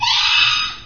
Scream 2.wav